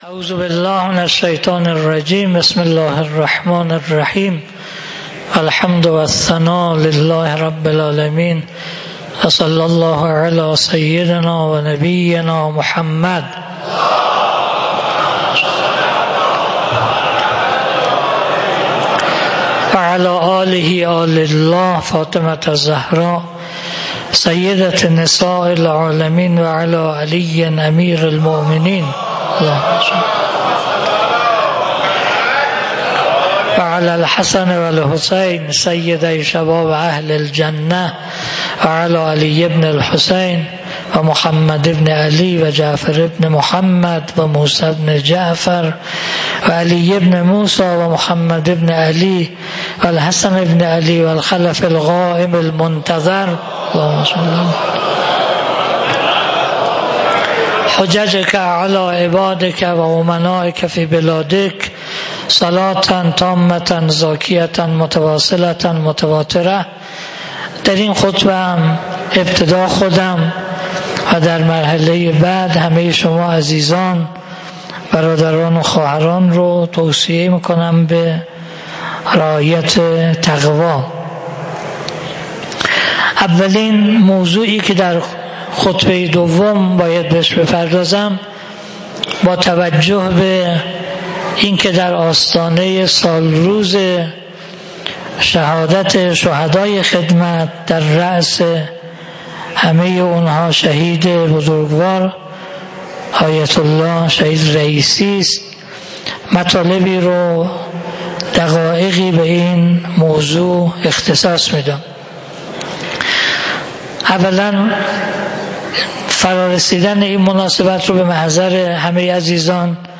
خطبه-دوم-2.mp3